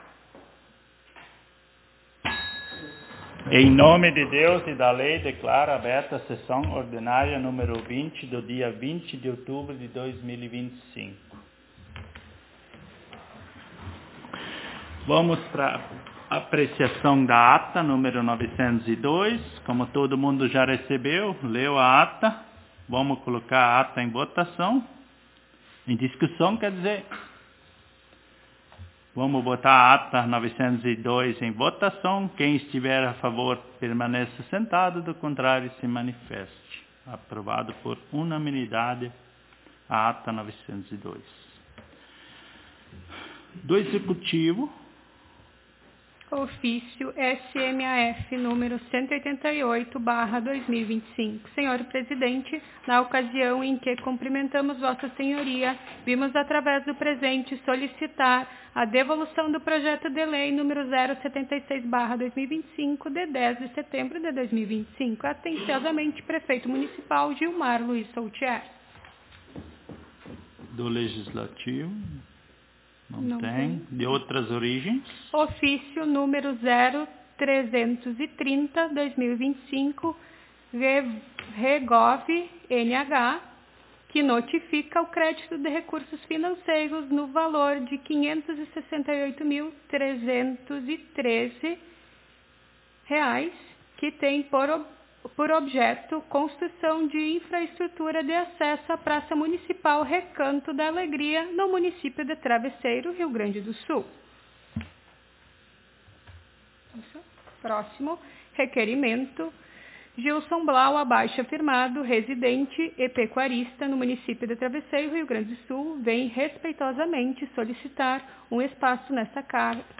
SESSÃO ORDINÁRIA Nº 20 - Legislatura 2025-2028
Aos 20 (vinte) dia do mês de outubro do ano de 2025 (dois mil e vinte e cinco), na Sala de Sessões da Câmara Municipal de Vereadores de Travesseiro/RS, realizou-se a Vigésima Sessão Ordinária da Legislatura 2025-2028.